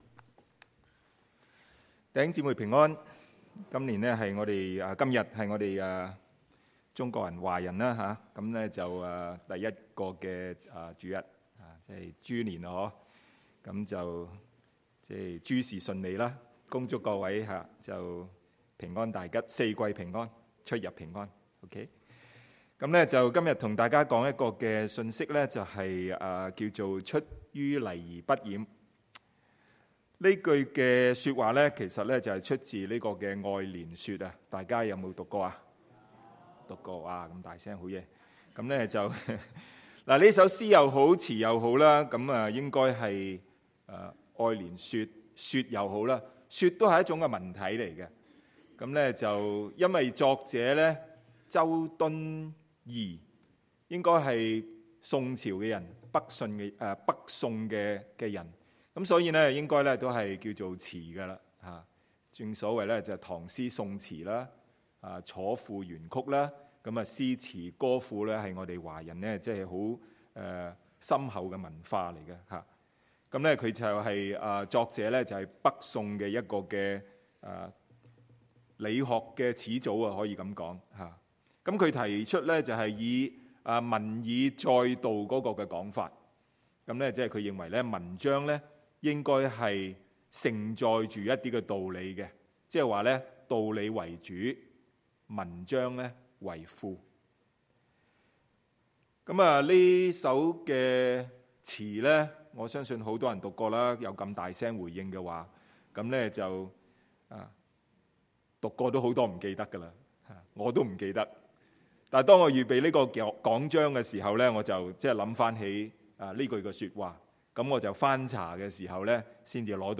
Sermons | Fraser Lands Church 菲沙崙教會